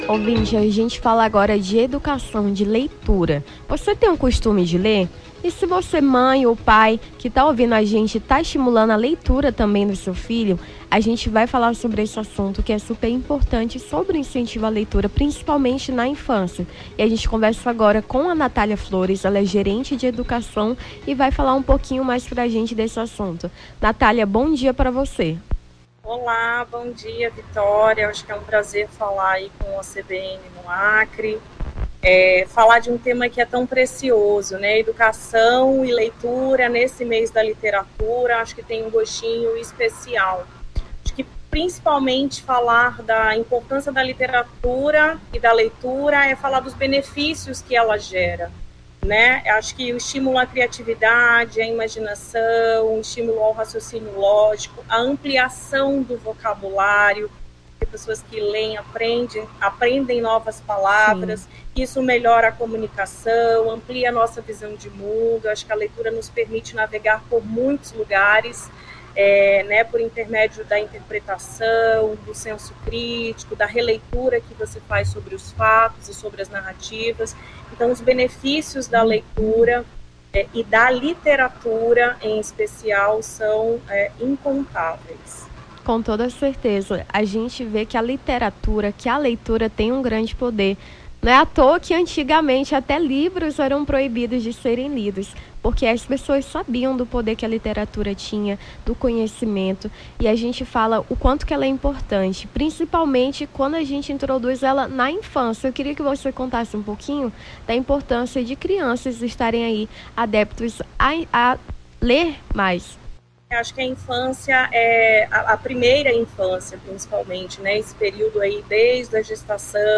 Nome do Artista - CENSURA - ENTREVISTA IMPORTÂNCIA DA LEITURA (15-05-24).mp3